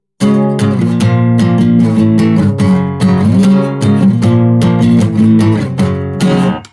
brainstorming ideas for a grunge riff